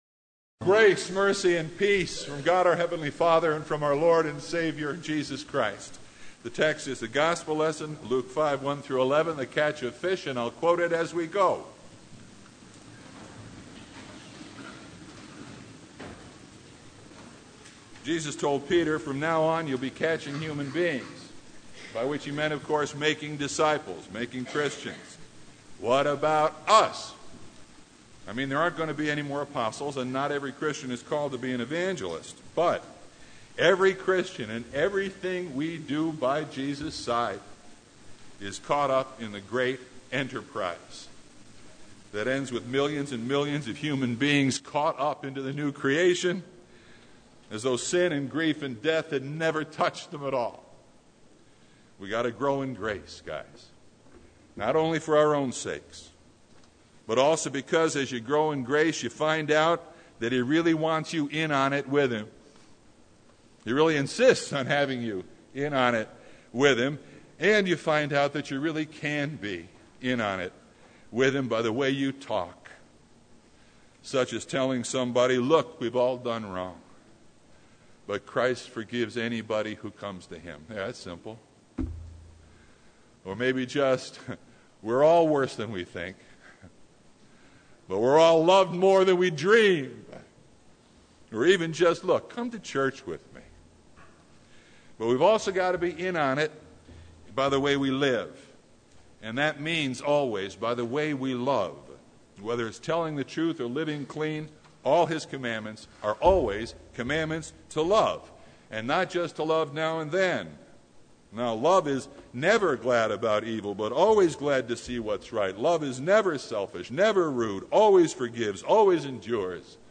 Service Type: Sunday
Sermon Only